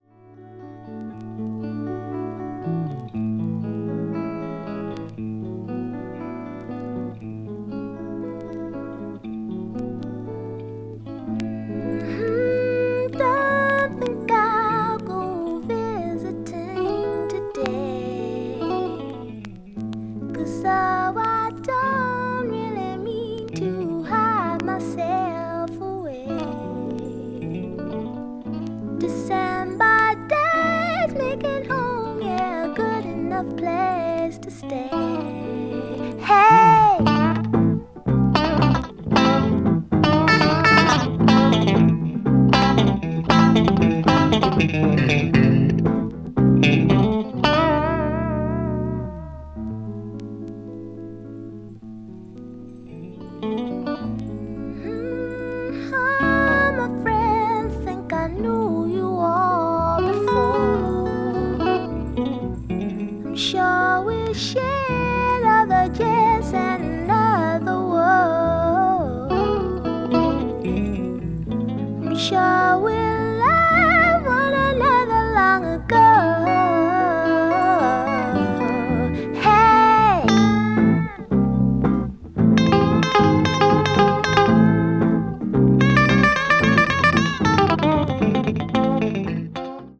folk   mellow groove   r&b   soul